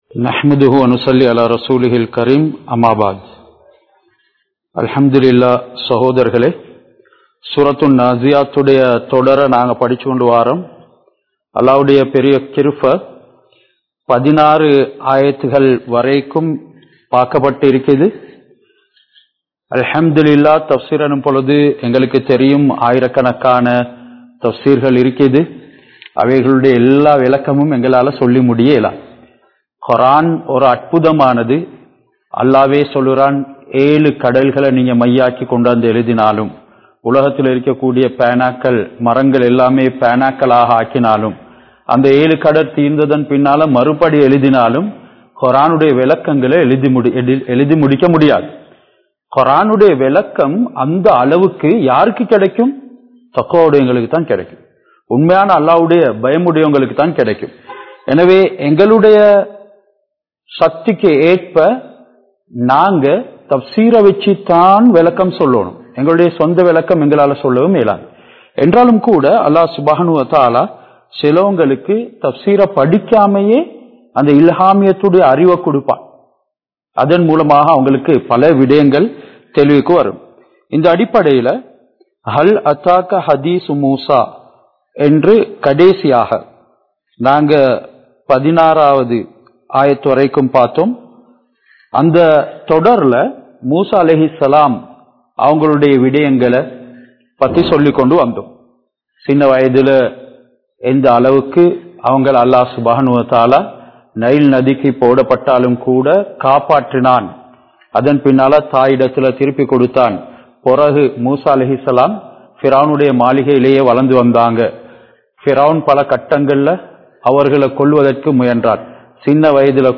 Surah An Naaziyath(Thafseer Versus 16-25) | Audio Bayans | All Ceylon Muslim Youth Community | Addalaichenai
Hameed Hall Furqaniyyah Arabic College